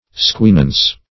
Squinance \Squin"ance\ (skw[i^]n"ans), Squinancy \Squin"an*cy\